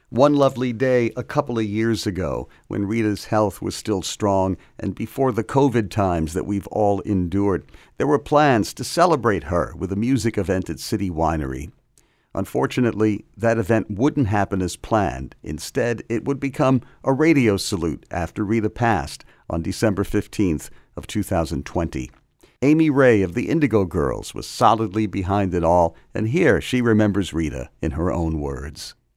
(web stream capture)
02. introduction of amy ray (0:29)